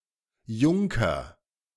Se denomina en alemán: Junker, pronunciado